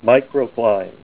Help on Name Pronunciation: Name Pronunciation: Microcline + Pronunciation
Say MICROCLINE Help on Synonym: Synonym: Amazonstone - green